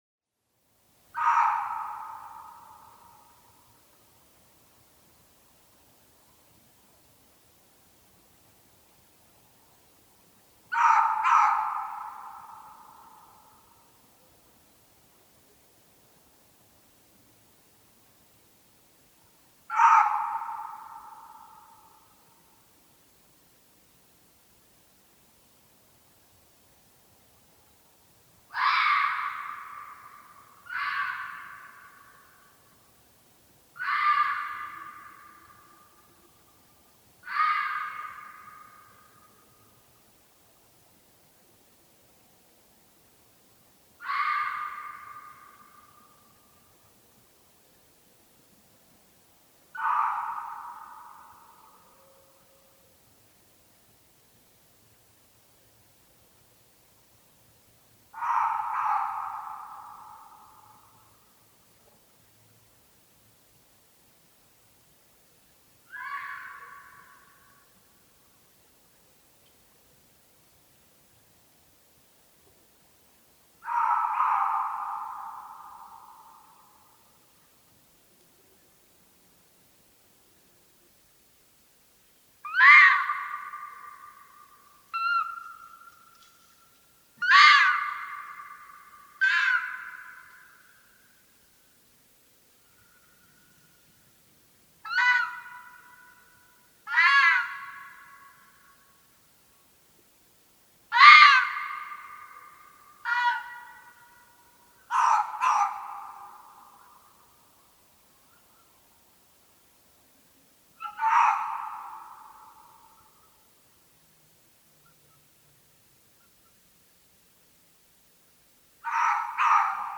TIERLAUTE
Rotfuchs
1722_Rotfuchs_Ranz_short.mp3